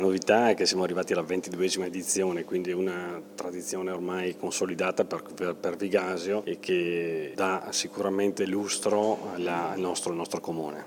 La Sala Rossa della Provincia di Verona ha ospitato venerdì 28 settembre la conferenza stampa per la presentazione della prossima edizione della manifestazione “ Fiera della Polenta” di Vigasio che si terrà dall’11 ottobre al 4 novembre 2018.
Per l’occasione abbiamo intervistato il sindaco di Vigasio Eddi Tosi:
sindaco-eddi-tosi.mp3